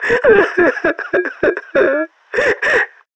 NPC_Creatures_Vocalisations_Robothead [98].wav